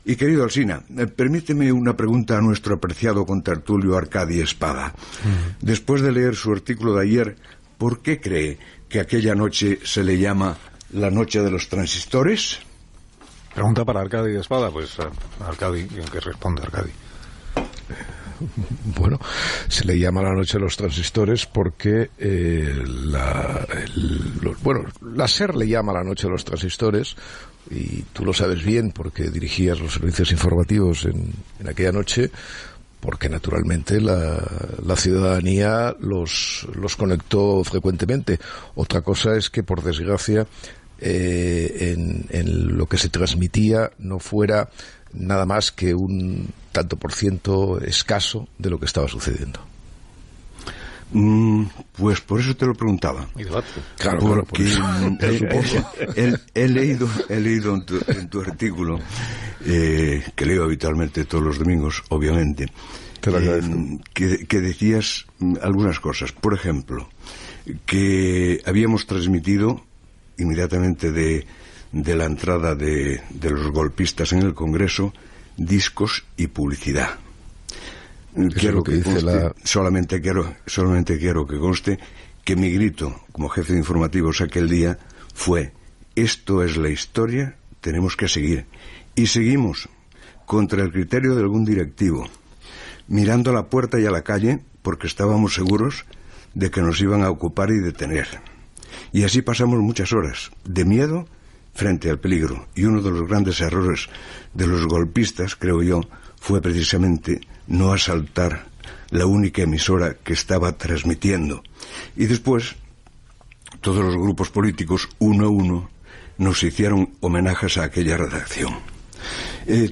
Fernando Ónega puntualitza i aclareix el tractament informatiu que va fer la Cadena SER, el 23 de febrer de 1981, quan es va produir l'intent de cop d'Estat amb l'assalt de la Guardia Civil al Congés de Diputats Gènere radiofònic Info-entreteniment Presentador/a Alsina, Carlos Ónega, Fernando Espada, Arcadi